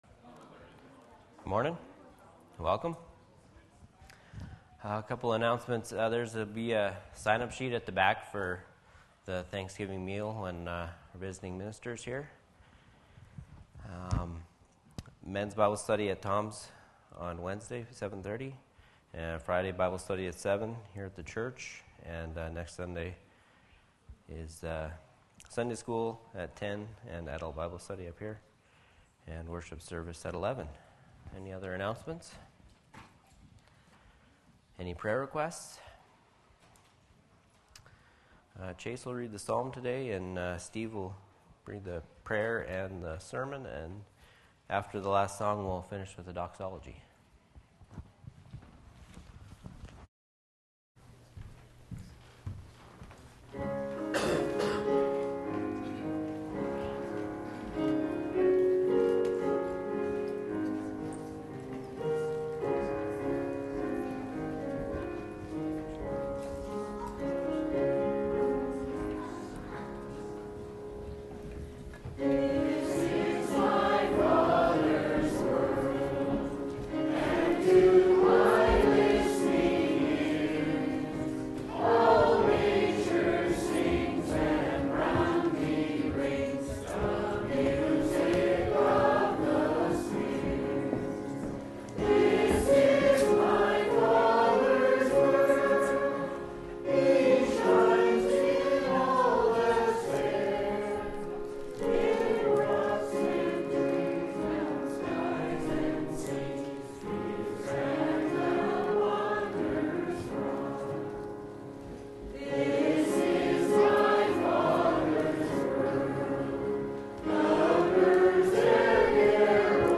Sunday Worship